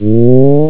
sfx_heal.wav